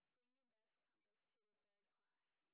sp27_exhibition_snr30.wav